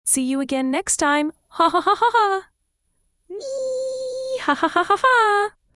ttsMP3.com_VoiceText_2025-9-5_17-30-44.mp3